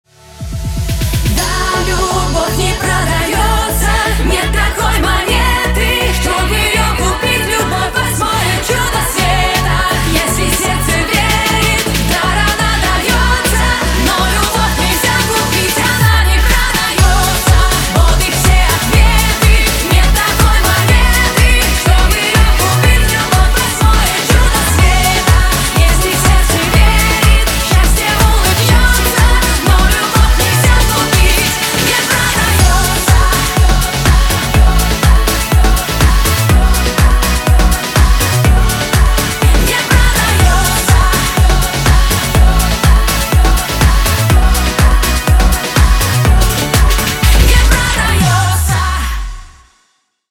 • Качество: 320, Stereo
поп
энергичные
энергичный припев песни